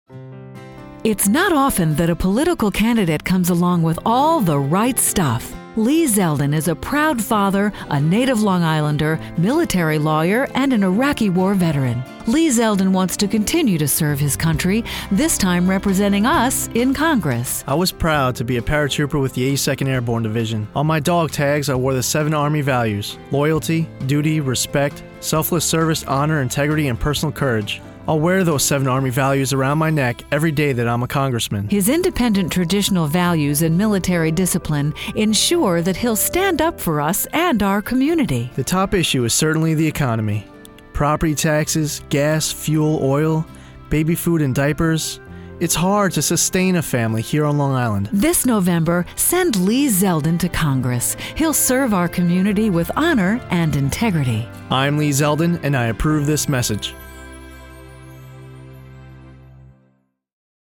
Zeldin Begins Airing Radio Commercials
leezeldinradio_final.mp3